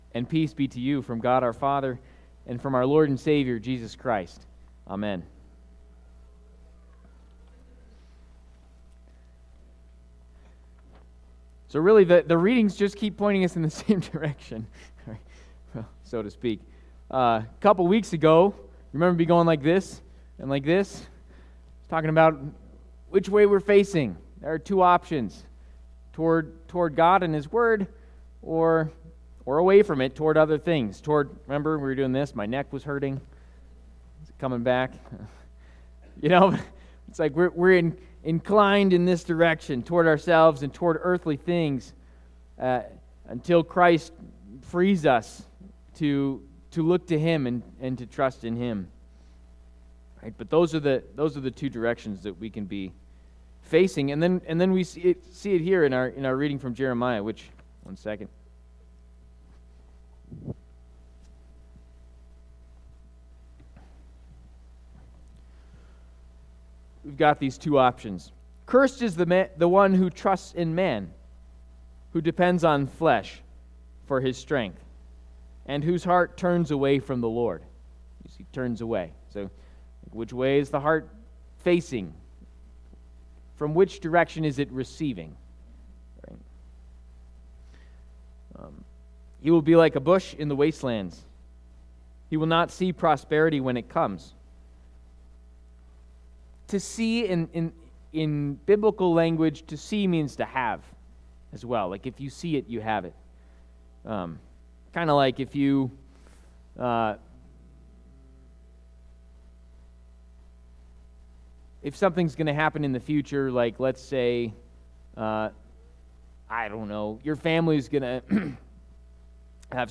Trinity Lutheran Church, Greeley, Colorado A Shrub in the Wilderness Feb 16 2025 | 00:22:50 1x 00:00 / 00:22:50 Subscribe Share RSS Feed Share Link Embed